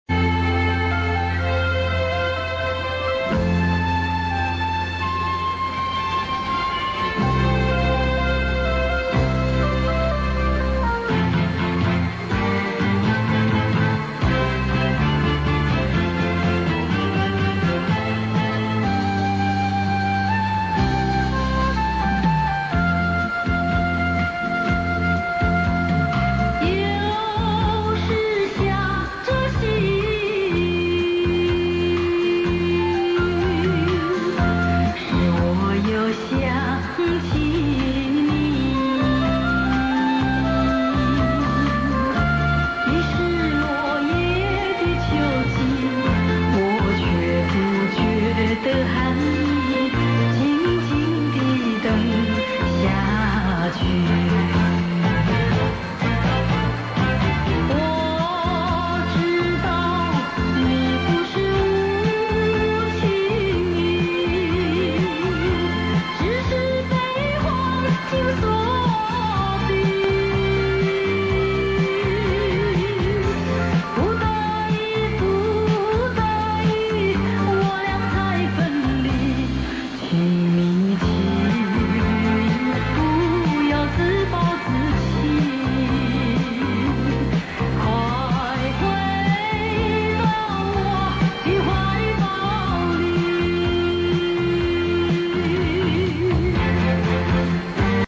这是本人录自电台的一组节目，由于录制于中波段，有些电磁干扰，见谅！ 每集一小时，感觉非常珍贵：主持人声音的甜美，歌曲异常的动听，要不是结束曲的响起，你可能还沉浸在魅力老歌之旅的路途上呢？